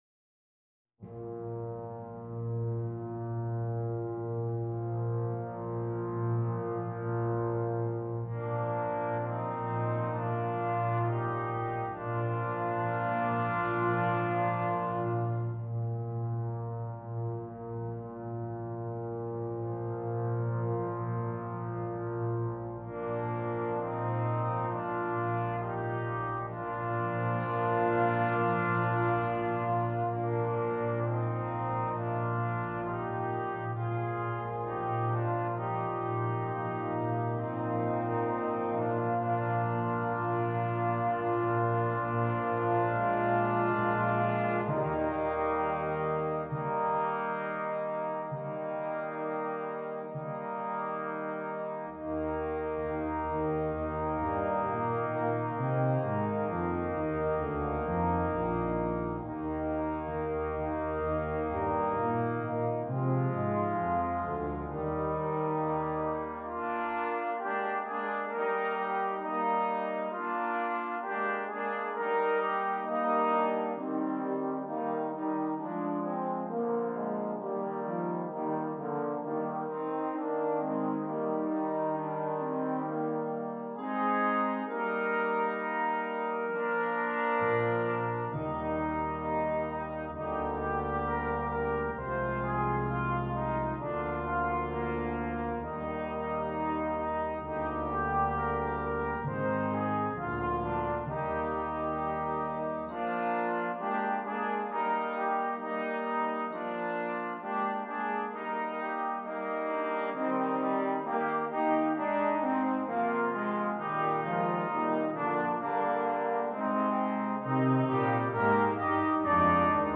Brass Band
Traditional English Folk Song
beautiful lyrical folk song